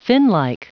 Prononciation du mot finlike en anglais (fichier audio)
Prononciation du mot : finlike